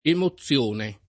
emozione [ emo ZZL1 ne ]